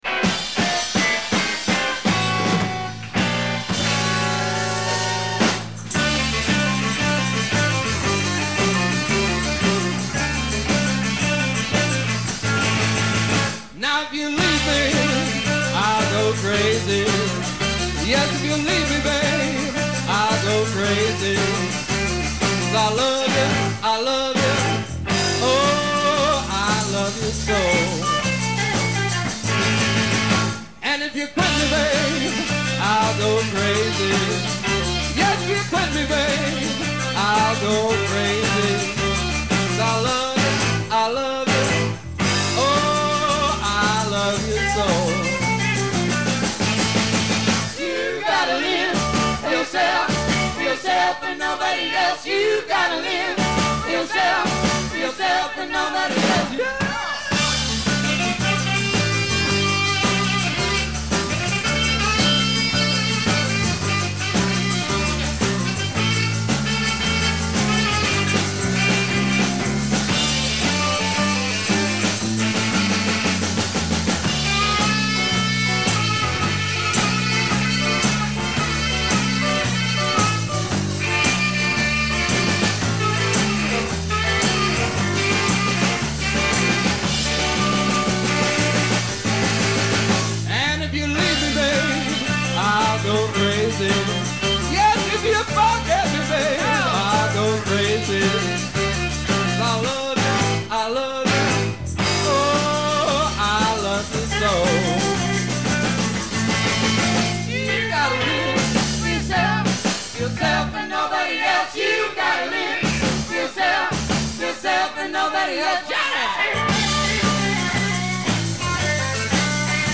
ends the medley